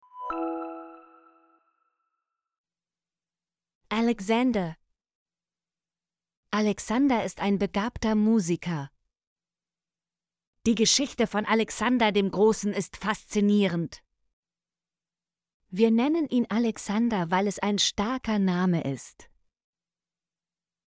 alexander - Example Sentences & Pronunciation, German Frequency List